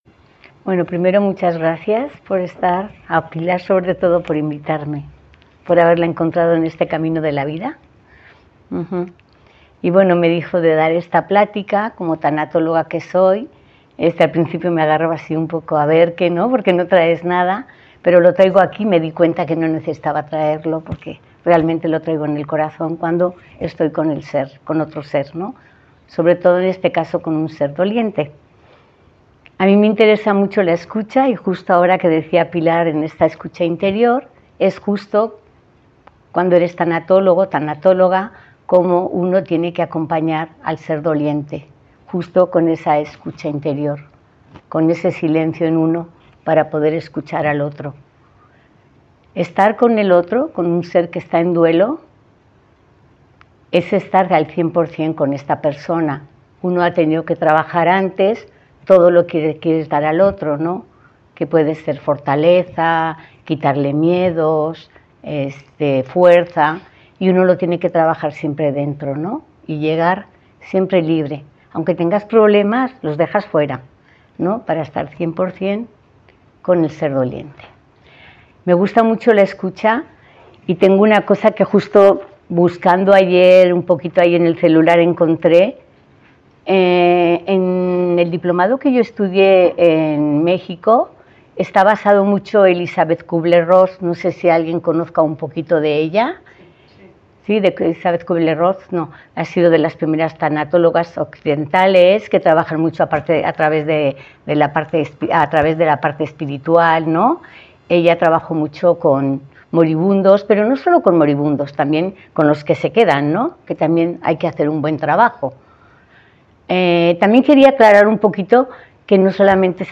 Quería compartir una pequeña reseña de esta charla-coloquio y el audio para que podáis escucharla los que no pudisteis asistir.